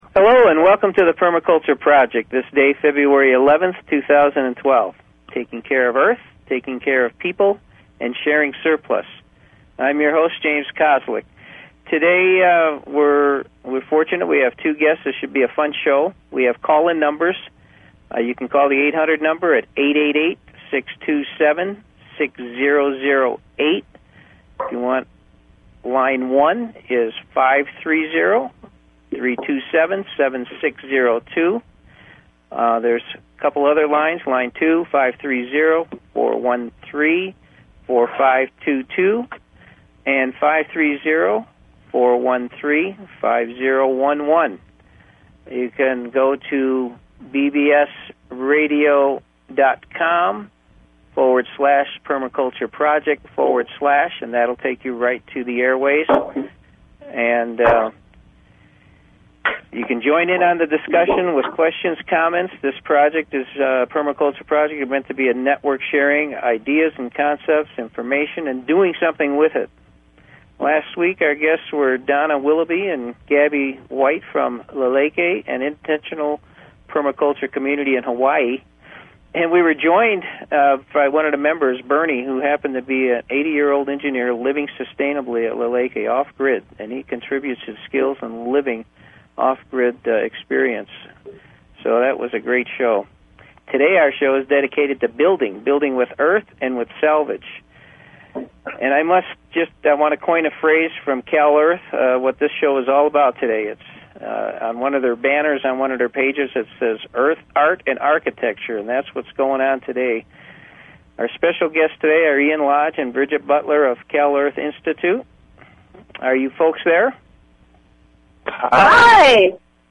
Talk Show Episode, Audio Podcast, Permaculture_Project and Courtesy of BBS Radio on , show guests , about , categorized as